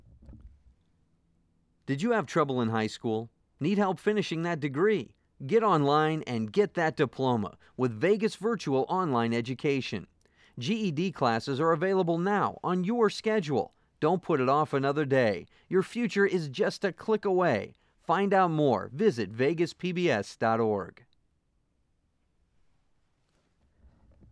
Voice Over